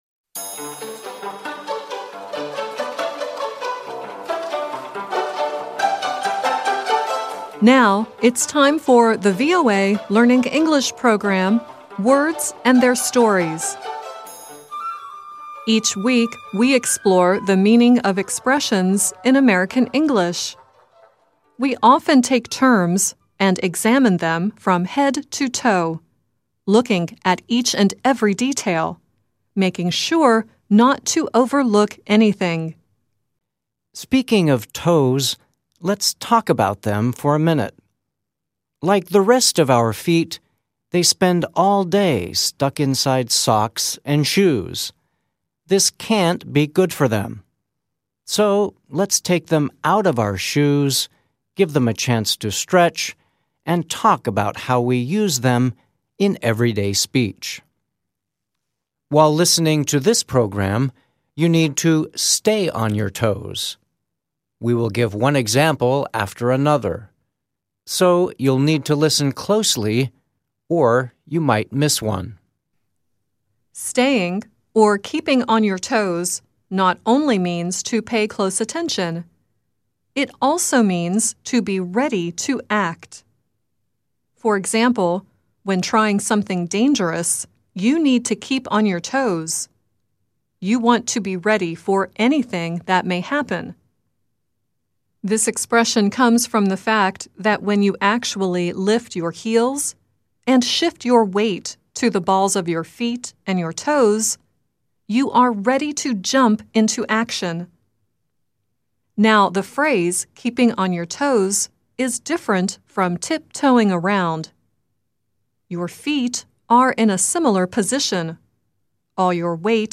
At the end of the audio story, Tiny Tim sings "Tiptoe Through the Tulips."